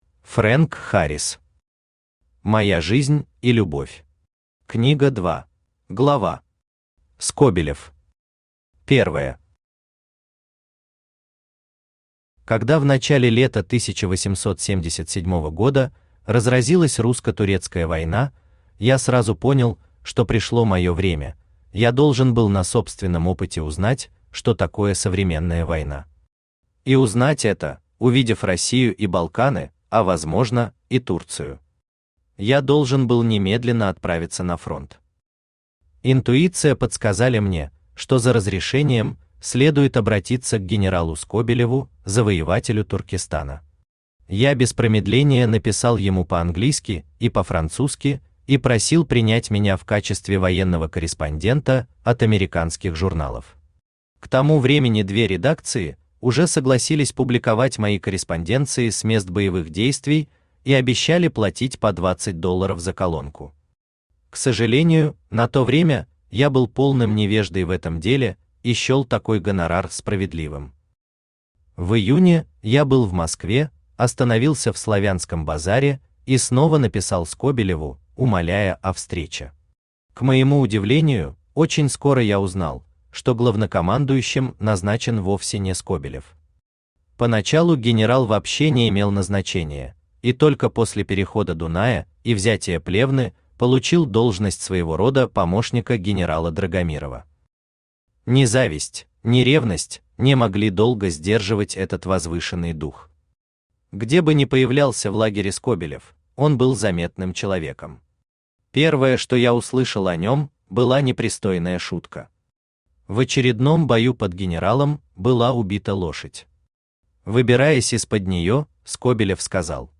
Аудиокнига Моя жизнь и любовь. Книга 2 | Библиотека аудиокниг
Aудиокнига Моя жизнь и любовь. Книга 2 Автор Фрэнк Харрис Читает аудиокнигу Авточтец ЛитРес.